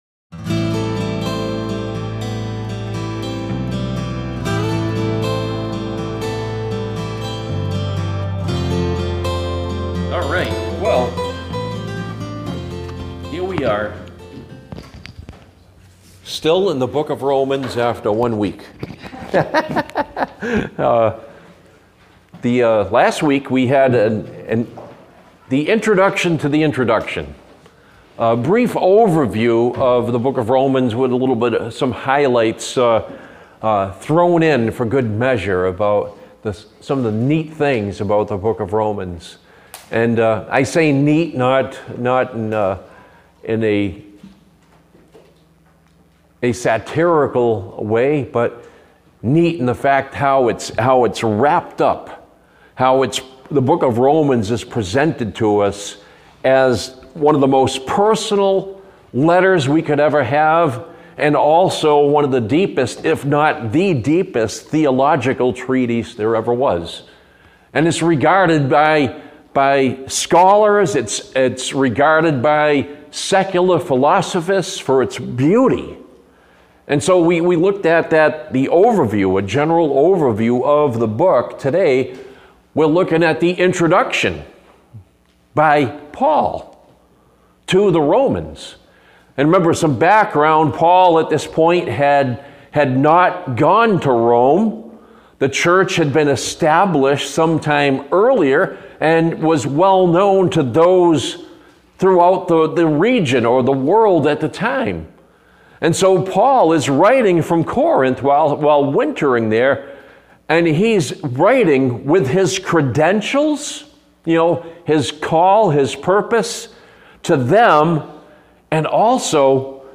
The Appointed Time | SermonAudio Broadcaster is Live View the Live Stream Share this sermon Disabled by adblocker Copy URL Copied!